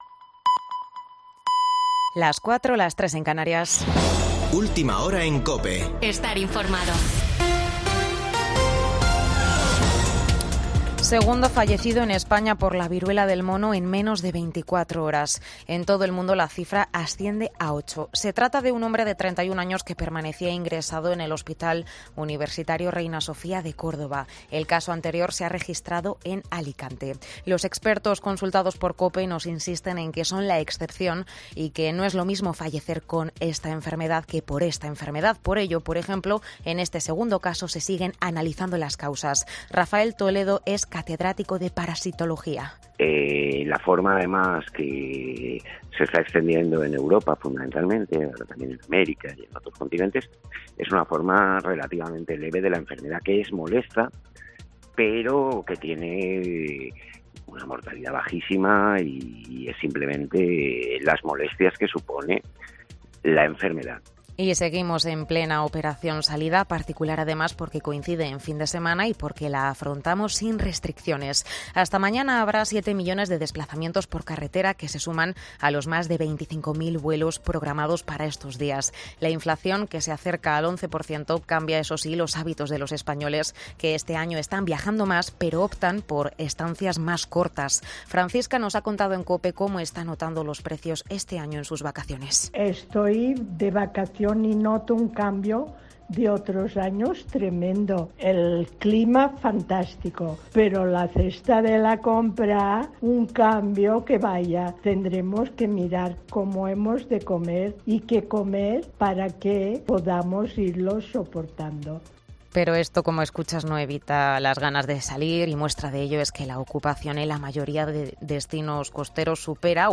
Boletín de noticias de COPE del 31 de julio de 2022 a las 04:00 horas